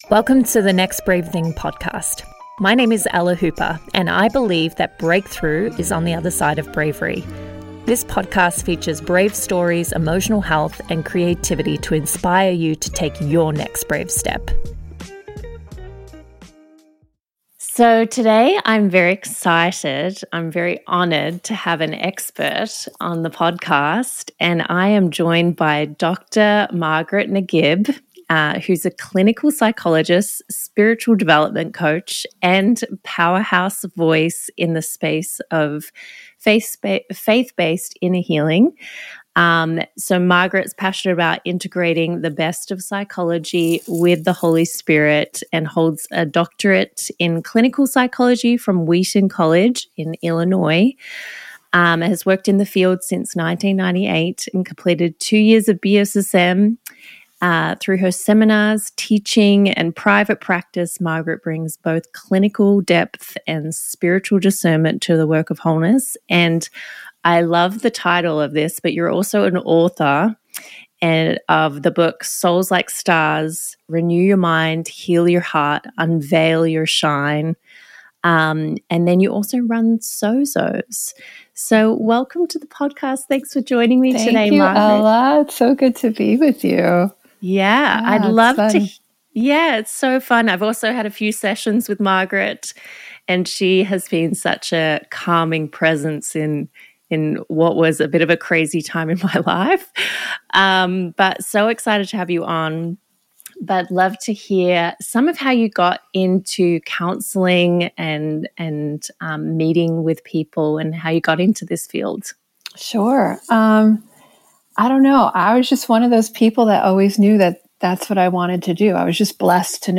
In this transformative conversation